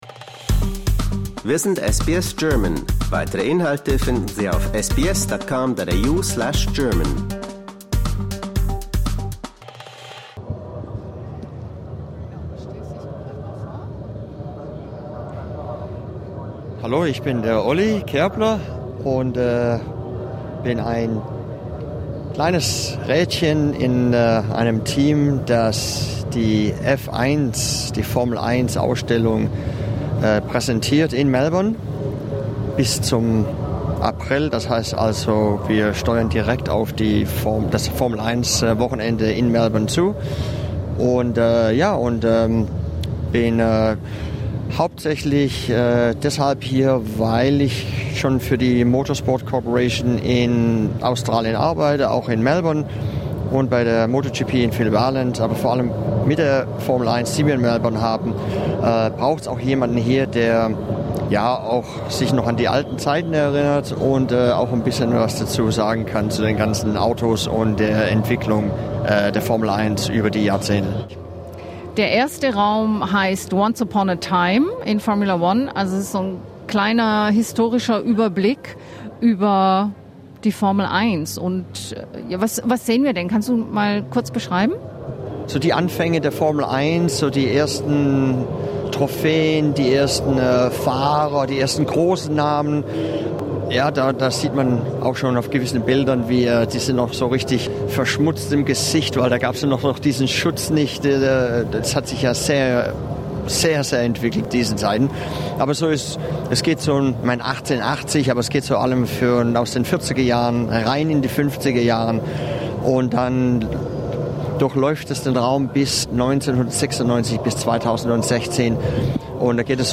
Formula One in Melbourne: exhibition tour with an F1 insider about history, Grand Prix and legends